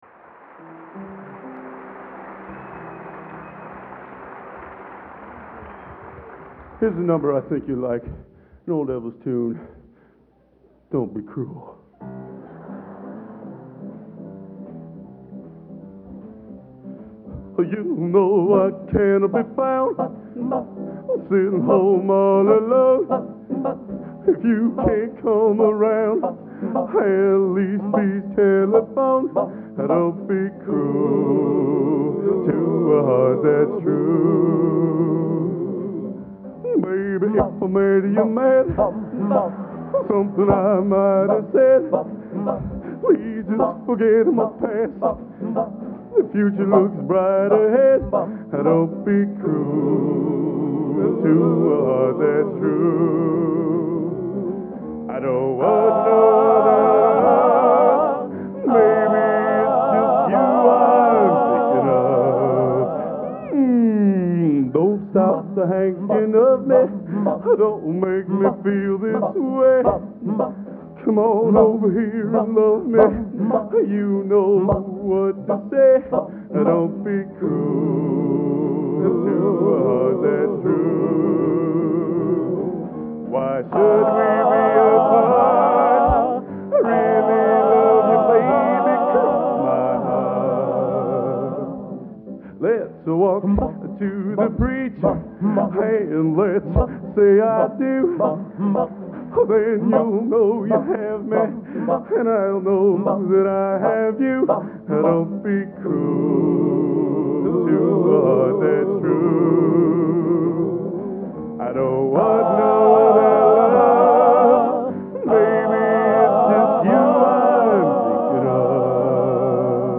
Location: West Lafayette, Indiana
Genre: Doo Wop | Type: End of Season |Specialty